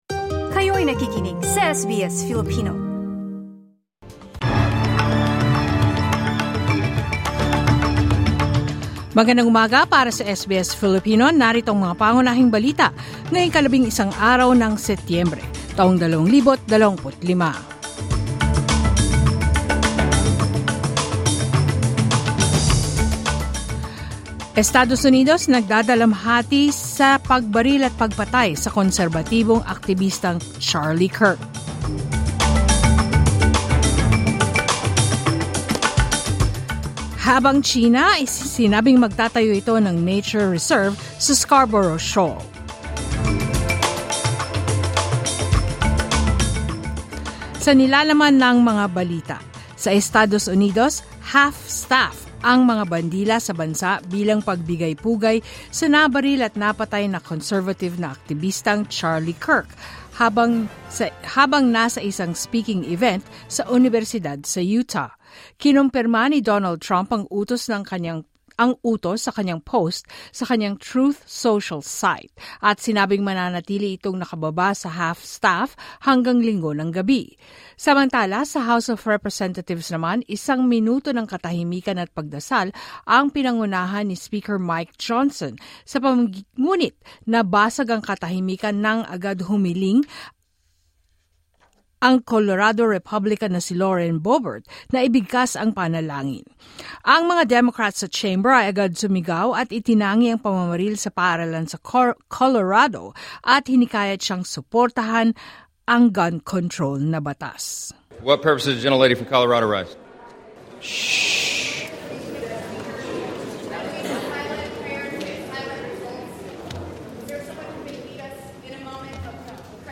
SBS News in Filipino Thursday, 11 September 2025